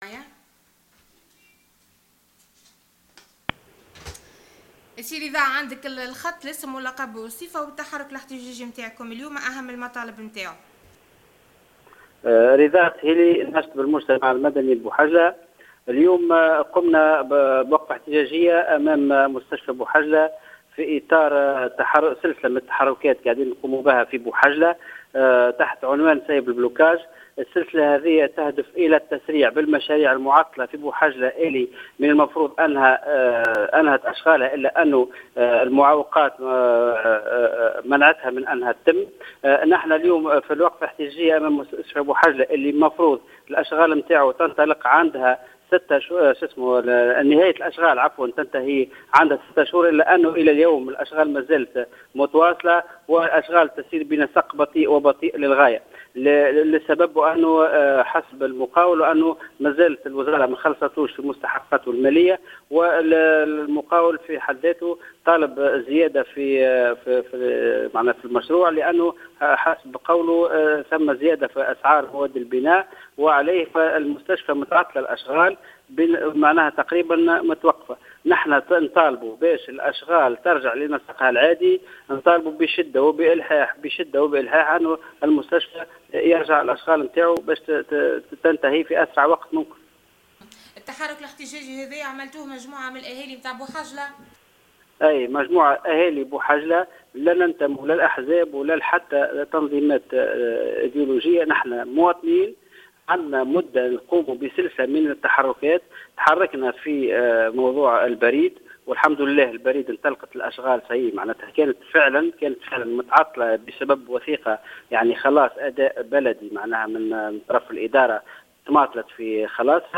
أحد المحتجين